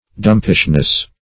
dumpishness - definition of dumpishness - synonyms, pronunciation, spelling from Free Dictionary
-- Dump"ish*ness , n. [1913 Webster]